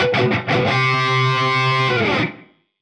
AM_HeroGuitar_85-C02.wav